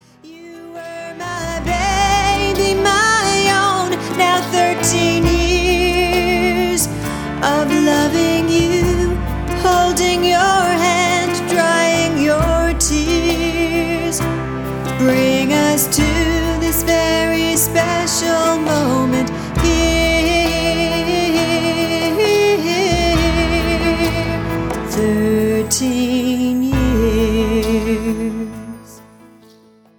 spirited, sincere songs
rich folk voice